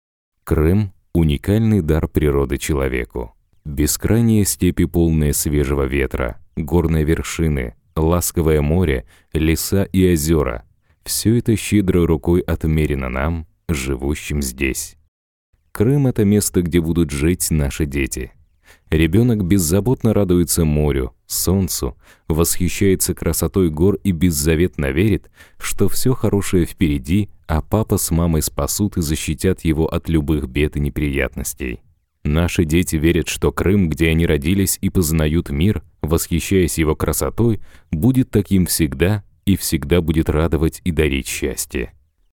Native Russian voicetalent, narrator, presenter.
Sprechprobe: Industrie (Muttersprache):